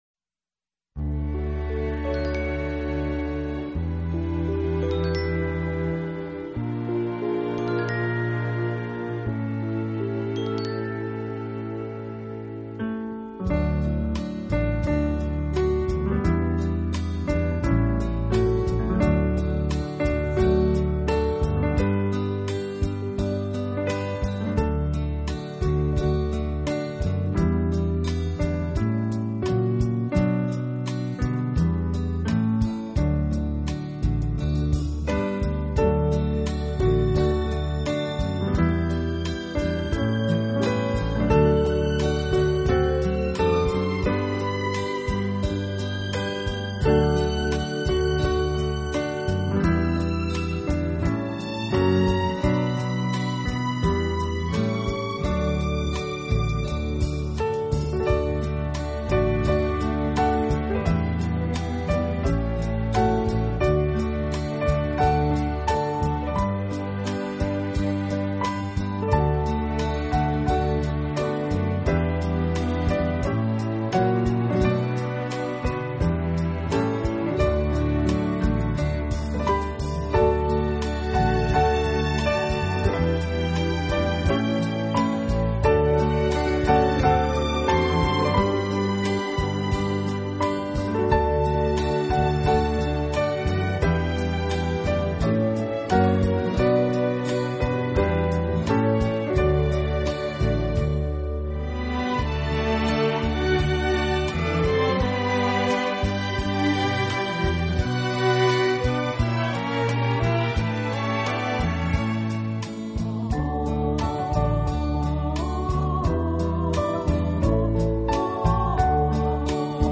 专辑歌手：纯音乐
湃；史上最浪漫、最优美钢琴名曲，超时空经典，超想像完美。